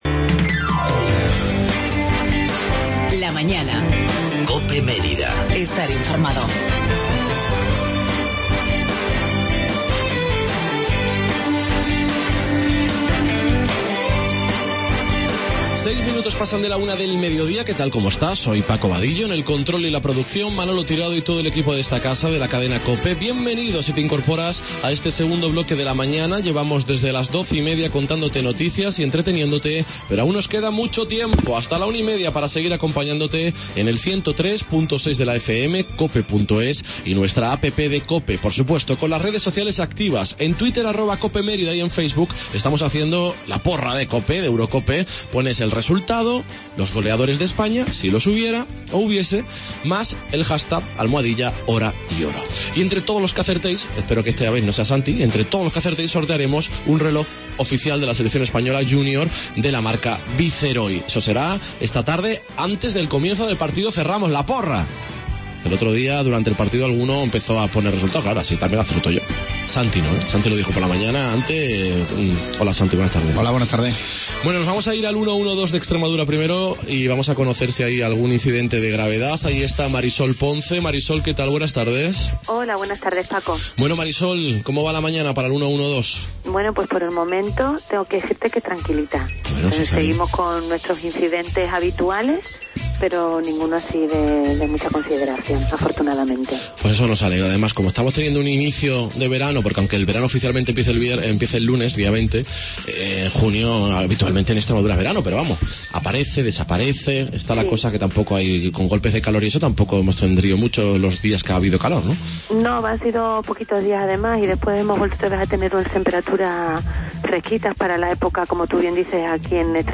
TERTULIA EUROCOPE EN COPE MÉRIDA 17-06-16